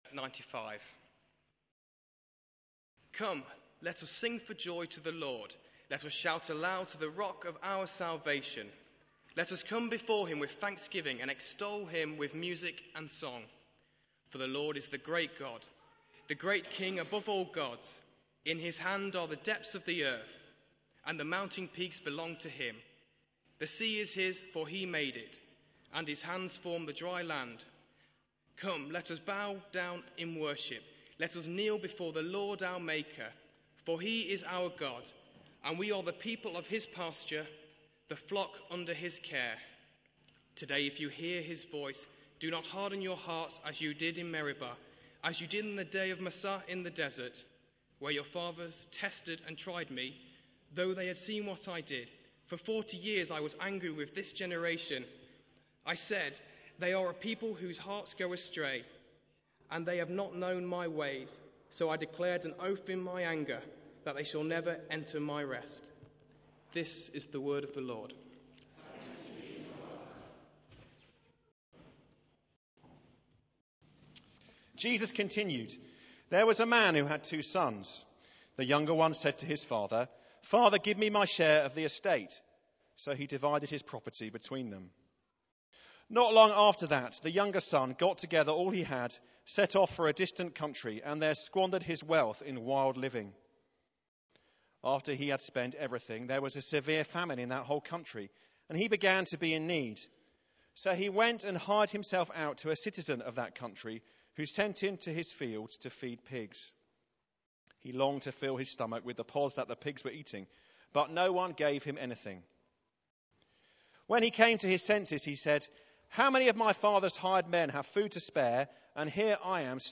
Talk by +Tony, Bishop of Sherwood, at the Deanery Confirmation Service at St John’s, at which 25 people from around Mansfield Deanery were Confirmed. Bible readings were Psalm 95, and Luke 15 verses 11-32.
Talk by Bishop Tony in 18:00 Evening Worship, St John's service